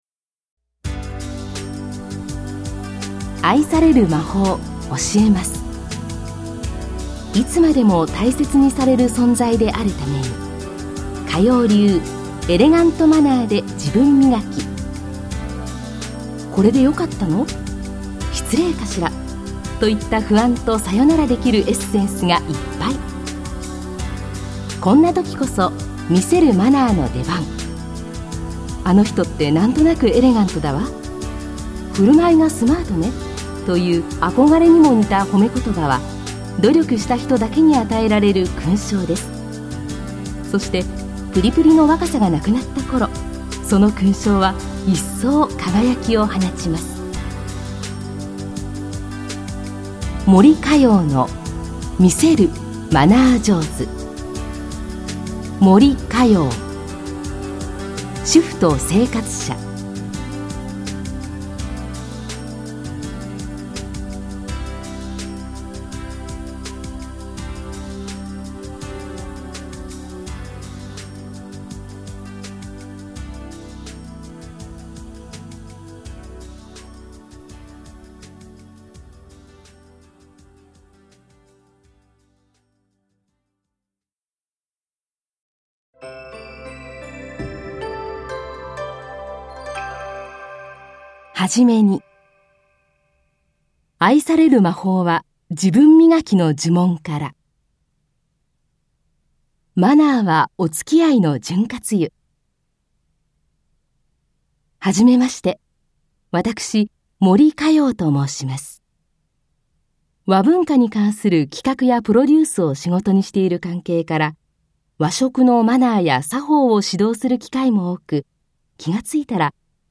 [オーディオブックCD] 森荷葉の「魅せる」マナー上手